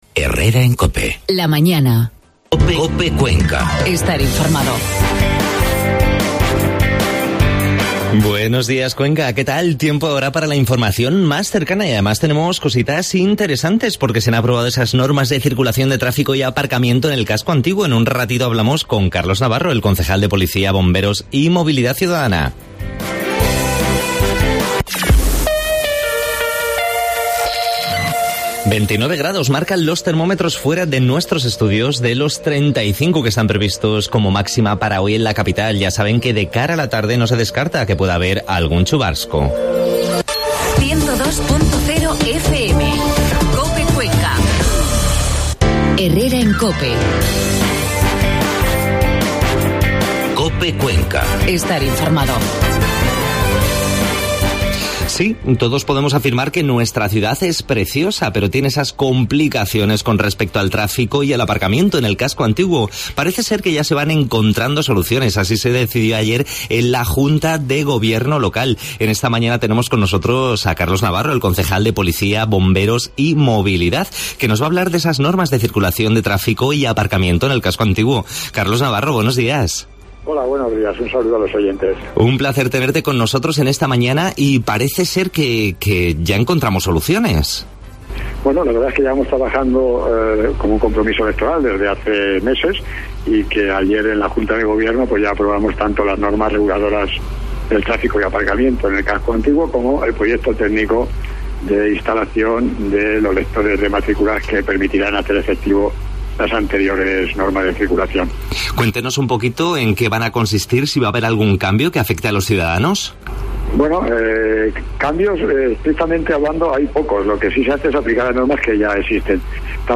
Entrevista a Carlos Navarro, concejal de policia, bomberos y movilidad del Ayuntamiento de Cuenca ante la aprobacion por parte de la Junta de Gobierno Local de las Normas de circulaciín de trafico y aparcamiento del casco antiguo, al igual que del proyecto tecnico de dispositivos lectores de matrículas.